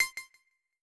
Success9.wav